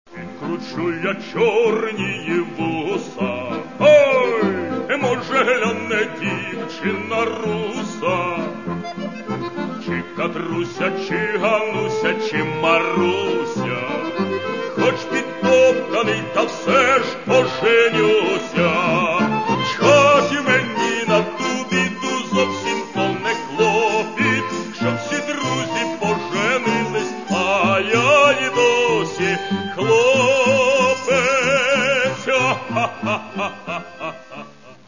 Українські народні жартівливі пісні.